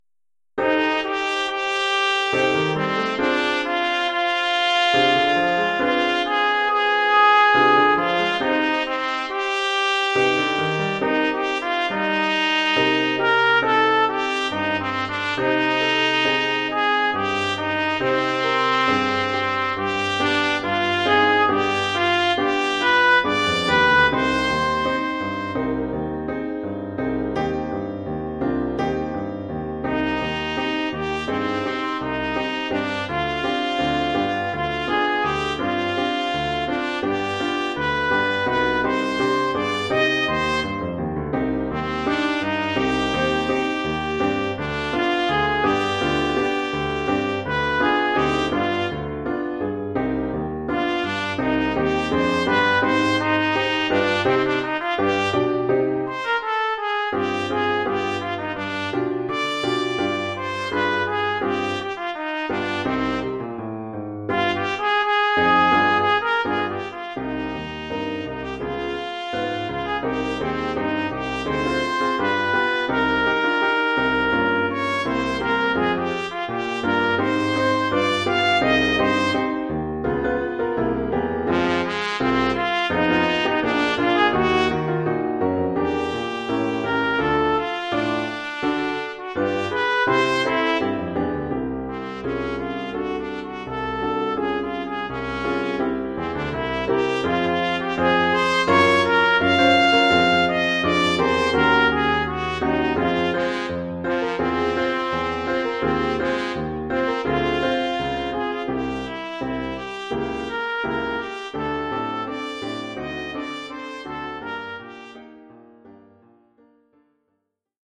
Oeuvre pour trompette ou cornet
ou bugle et piano..